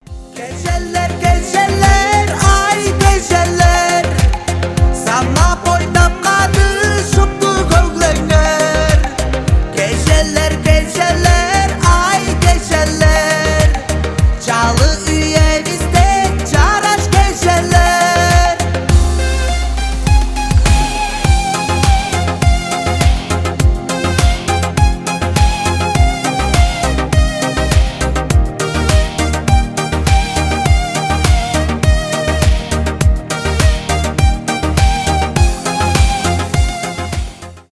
2024 » Поп Скачать припев